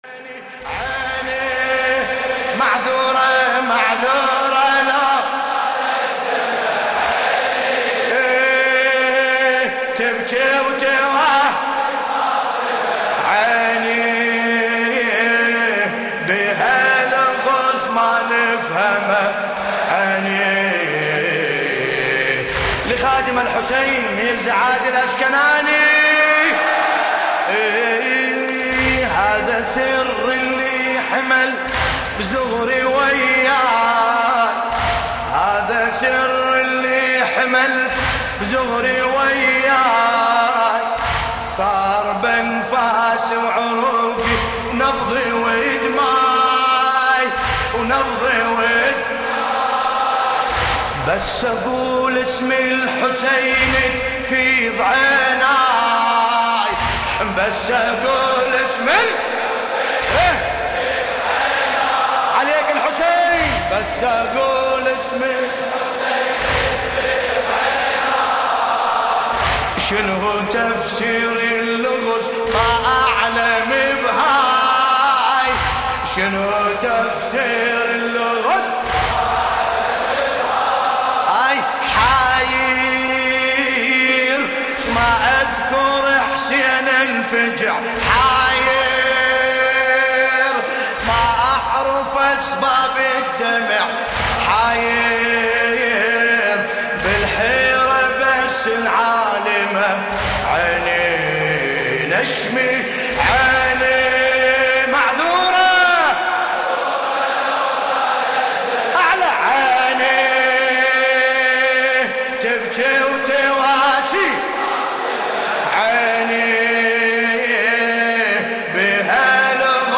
ملف صوتی عيني تبجي اوتواسي فاطمة بصوت باسم الكربلائي
قصيدة : عيني...معذوره لو سالت دمه...عيني
الرادود : الحاج ملا باسم الکربلائی